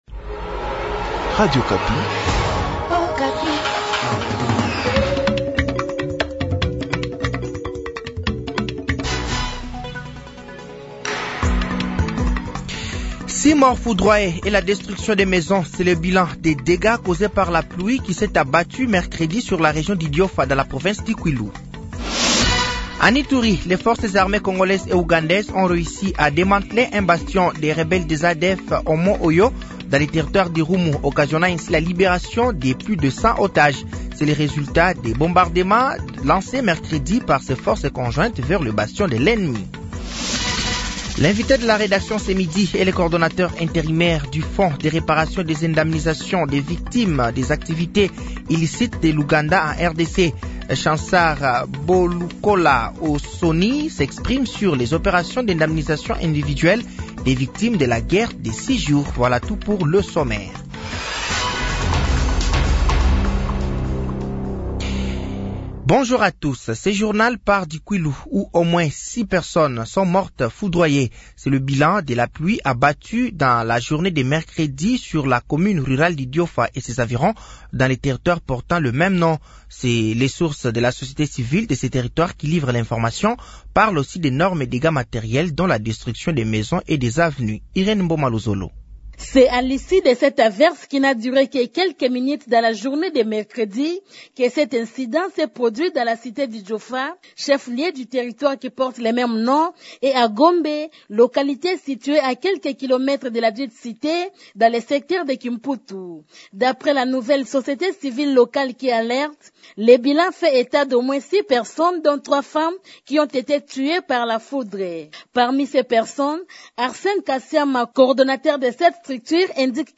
Journal midi
Journal français de 12h de ce vendredi 1er novembre 2024